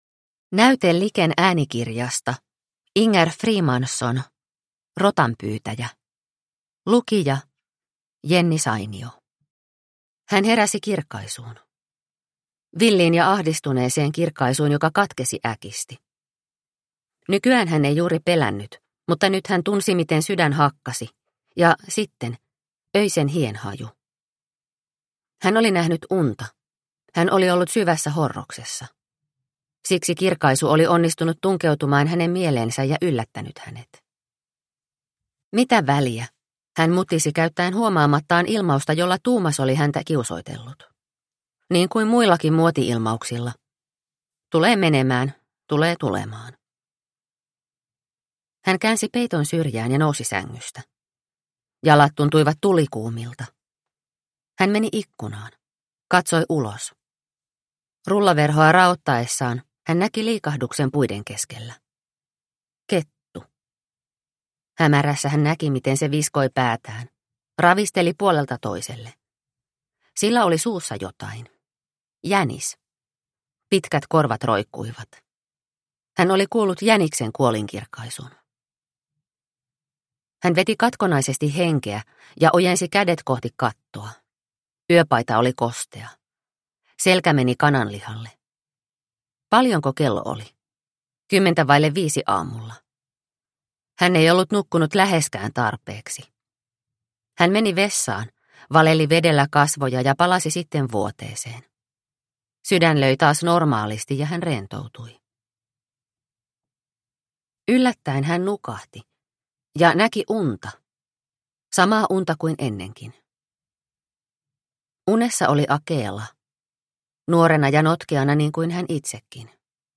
Rotanpyytäjä – Ljudbok – Laddas ner